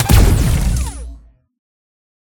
coilgunShoot.ogg